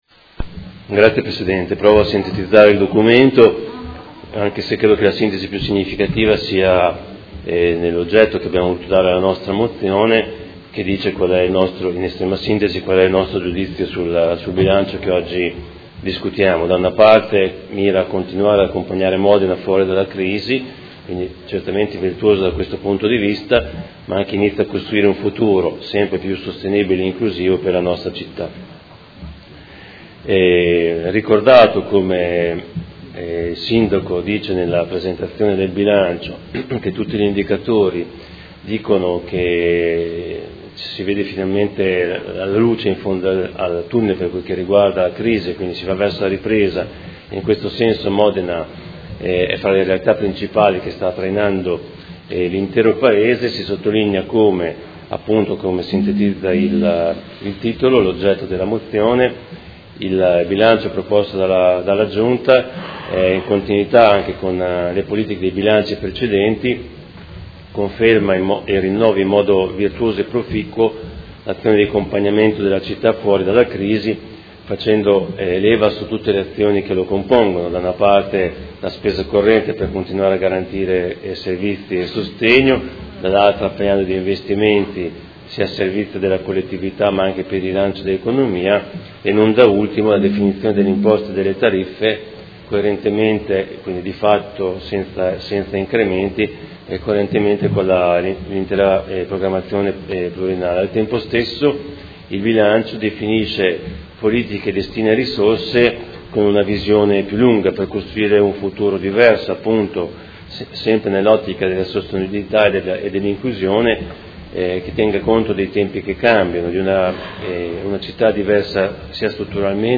Fabio Poggi — Sito Audio Consiglio Comunale